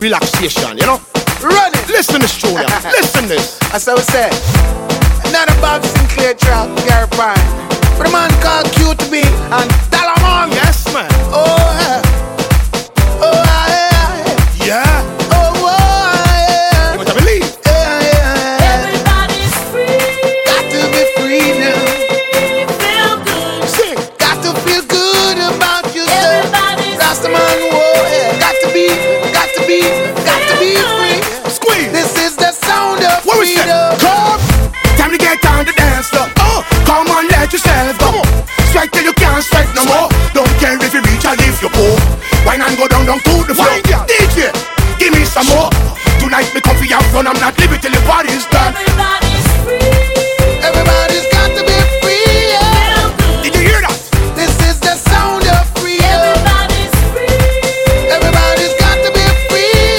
Электронная
Лучшая танцевальная музыка!